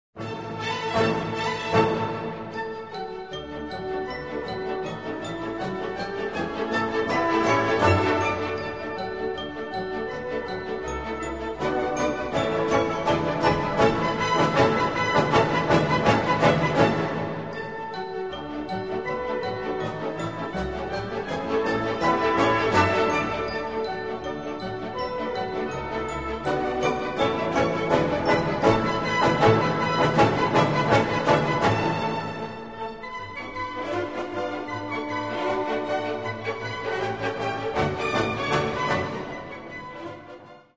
Catalogue -> Other -> Relax-piano, music therapy